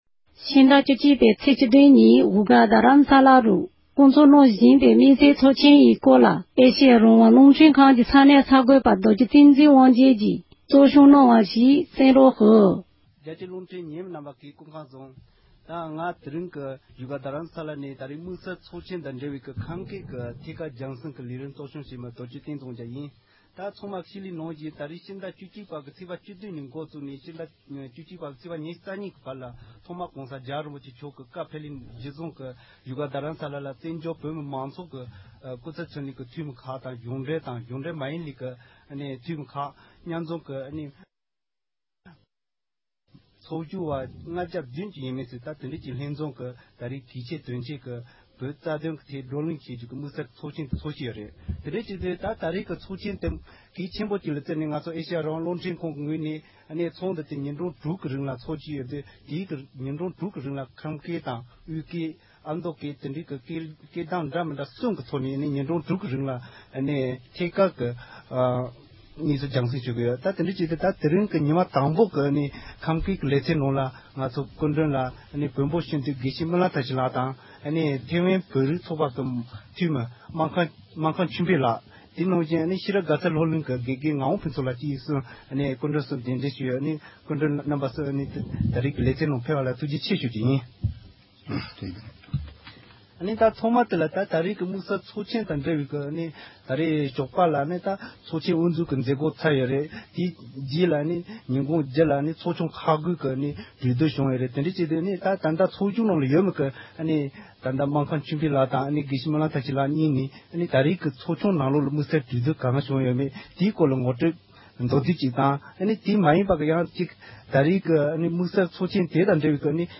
བཞུགས་སྒར་དུ་དམིགས་བསལ་ཚོགས་ཆེན་དབུ་བཙུཌ་གནང་ཡོད་པའི་སྐོར་འབྲེལ་ཡོད་མི་སྣ་ཁག་ལ་འདི་གའི་གསར་འགོད་པས་གླེང་མོལ་ཞུས་པ།